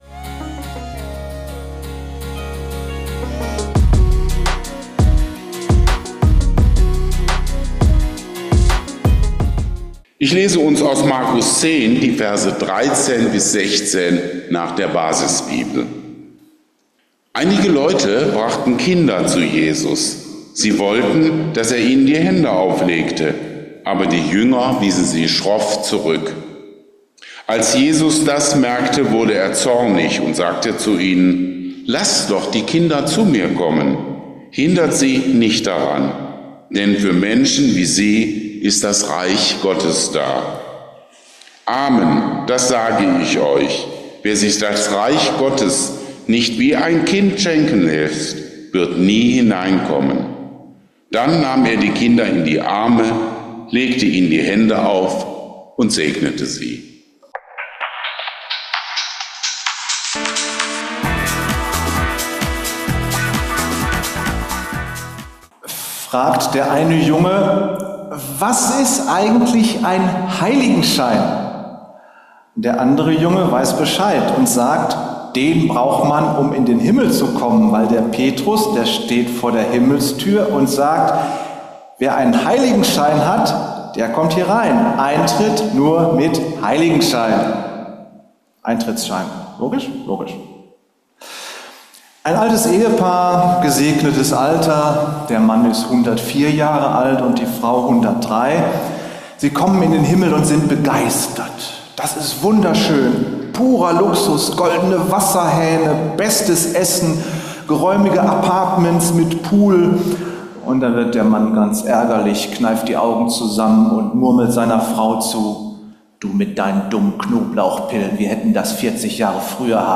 Am 11.8.2024 in der Freien ev. Gemeinde Wuppertal-Barmen.